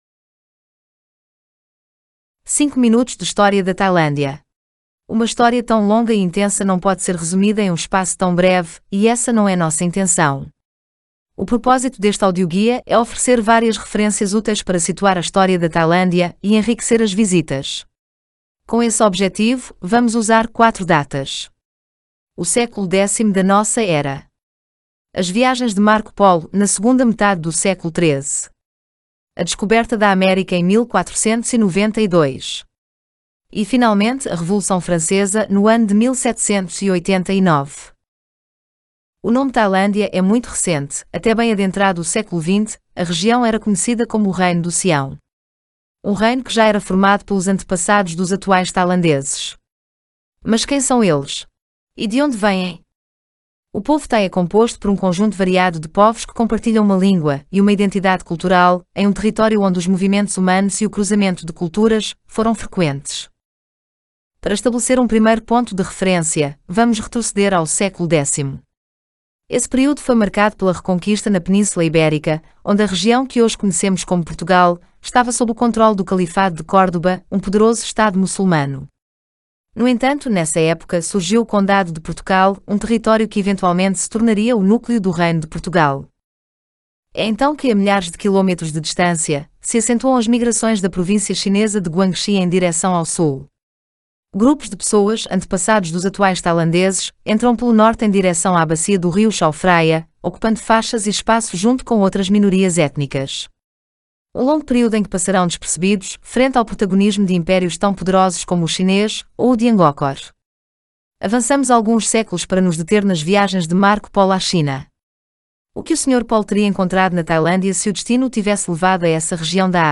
Como são os audioguias?